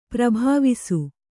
♪ prabhāvisu